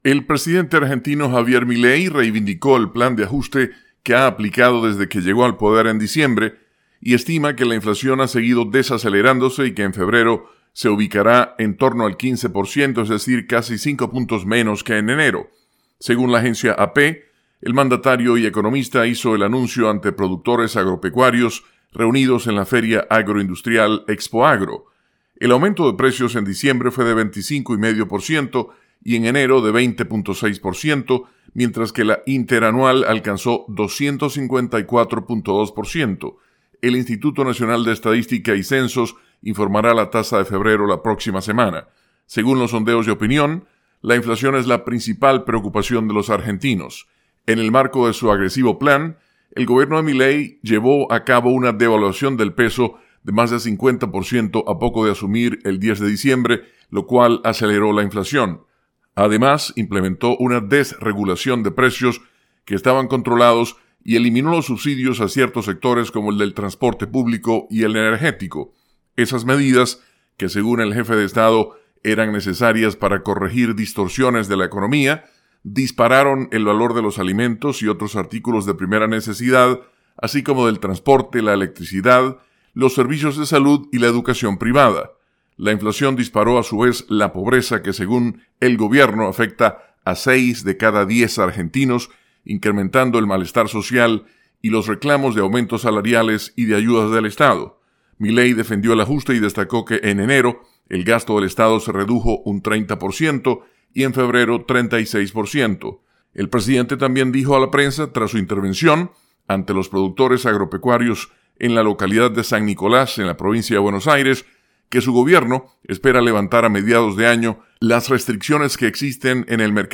Avance Informativo